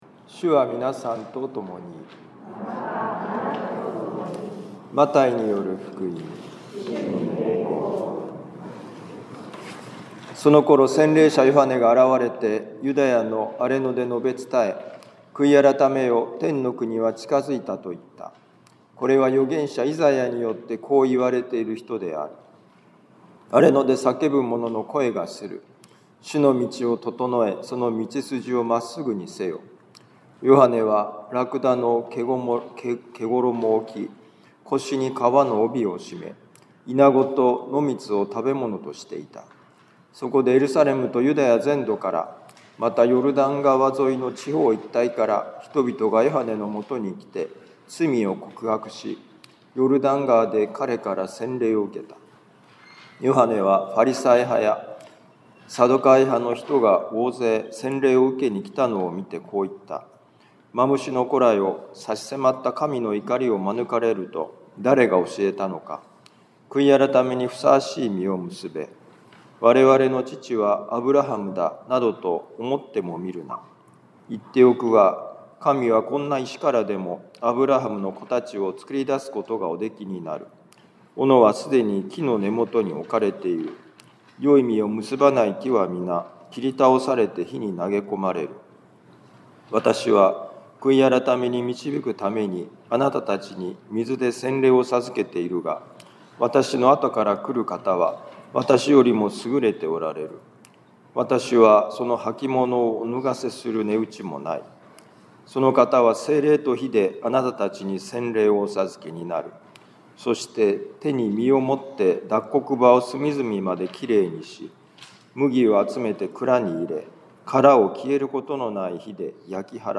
【ミサ説教】
マタイ福音書3章1-12節「荒れ野で生き方を立て直す」2025年12月7日待降節第2主日ミサ カトリック防符教会 今日の福音書朗読とお説教の聞きどころ 私たちは忙しさに追われて心が乱れると、「荒れ野」に放り出される。